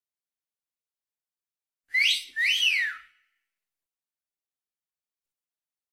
Sound Effect Siul 2x Untuk Sound Effects Free Download